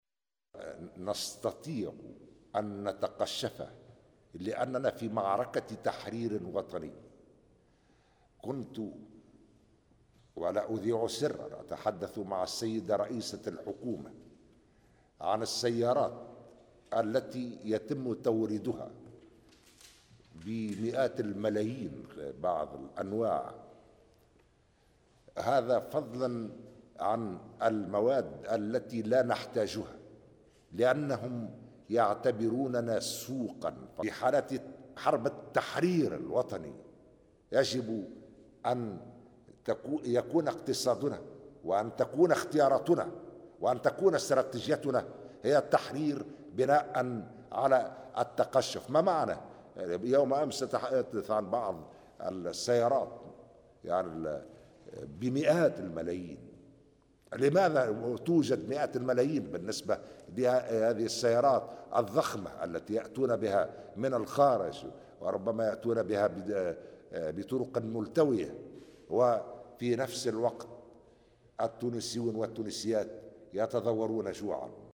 وقال خلال إشرافه اليوم على اجتماع مجلس الوزراء، " نستطيع أن نتقشف لأننا في معركة تحرير وطني و كنت أتحدث مع رئيسة الحكومة عن السيارات التي يتم توريدها بمئات الملايين فضلا عن المواد التي لا نحتاجها".